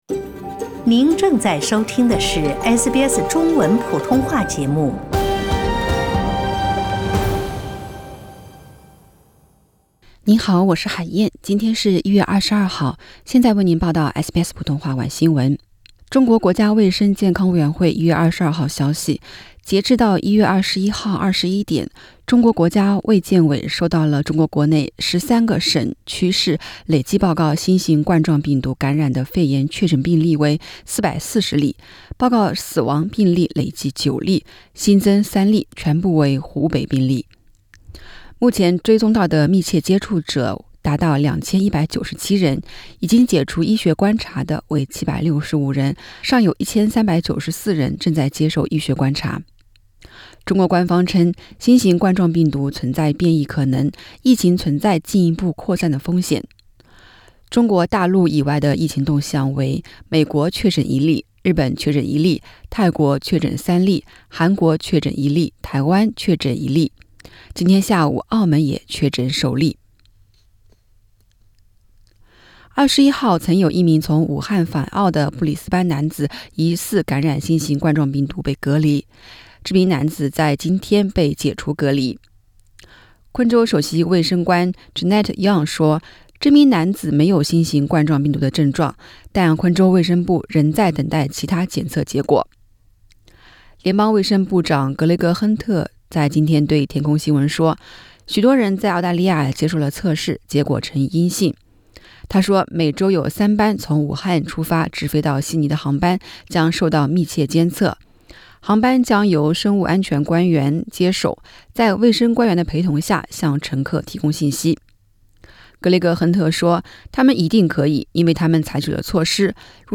新发现的病原体与SARS密切相关，可能属于同一种类病毒。请点击上方图片收听报道。